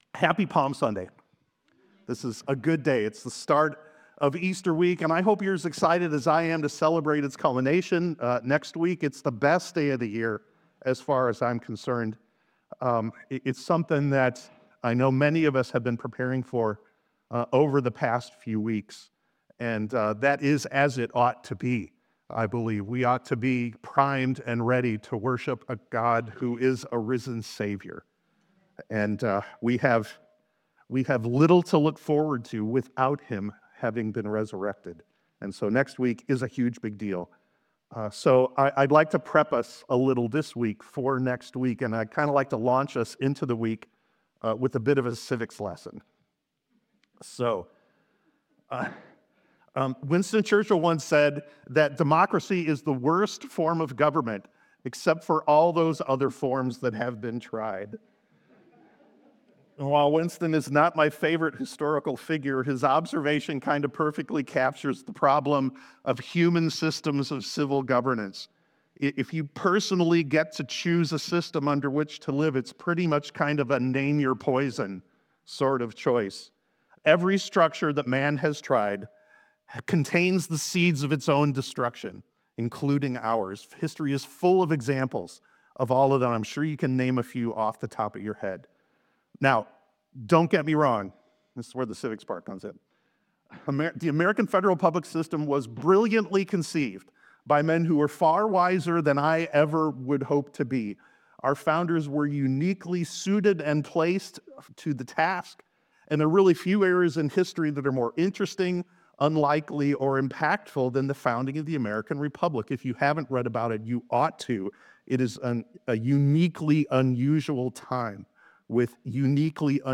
This Palm Sunday sermon explores the profound implications of Jesus' kingship through the lens of His triumphal entry into Jerusalem. Drawing from John 12:12-19, the message examines three distinct responses to Christ's authority: the Pharisees who didn't want a king and guarded their own autonomy, the crowds who wanted the wrong kind of king focused on temporal deliverance rather than spiritual transformation, and the disciples who failed to recognize they were walking with the King of Kings.